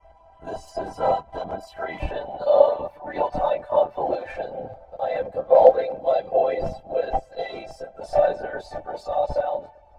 The plugin takes two real-time input tracks and convolves the most recent samples from both tracks together. It can be used for an effect similar to what a vocoder would produce.
Demonstration